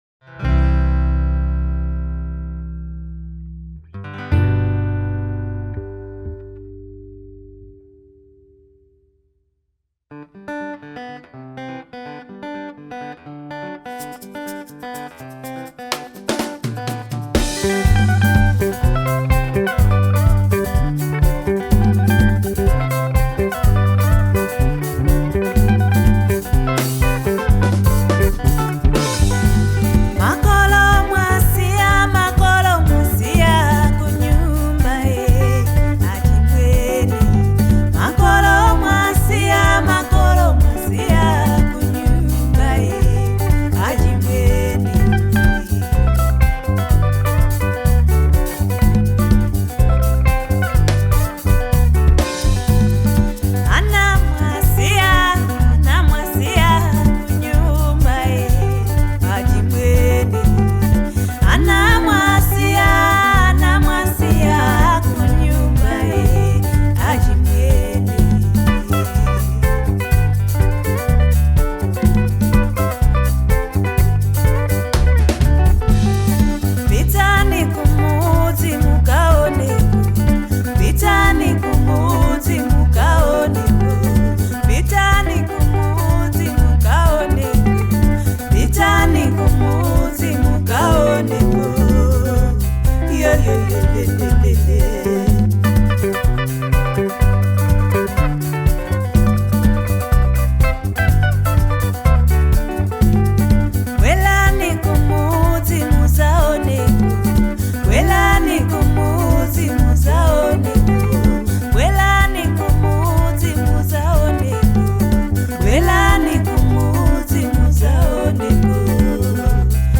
Afrobeat
With its upbeat tempo and catchy sounds